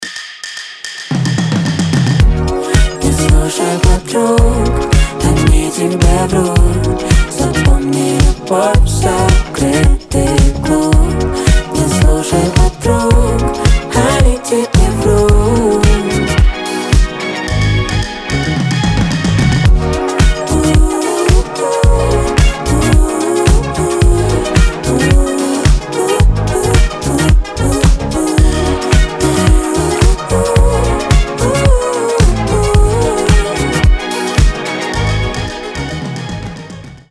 • Качество: 320, Stereo
диско
дуэт
Фанк